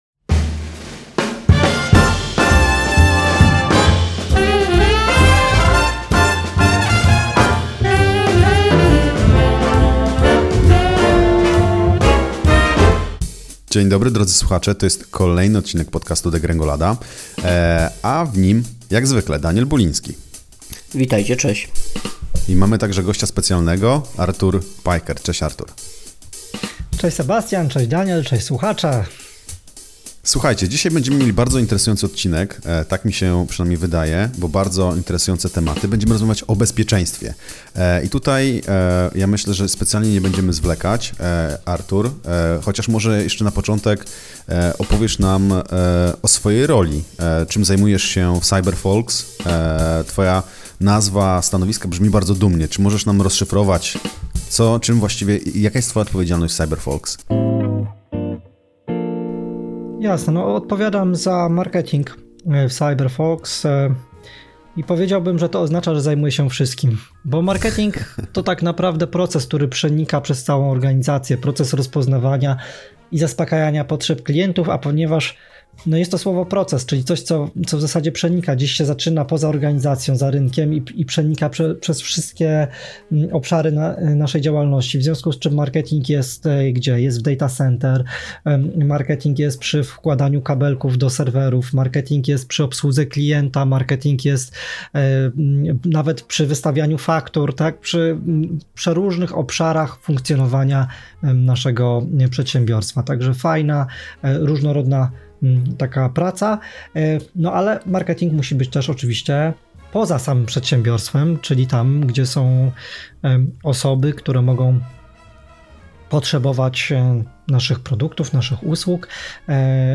[WYWIAD] Bezpieczeństwo własnej strony i sklepu internetowego?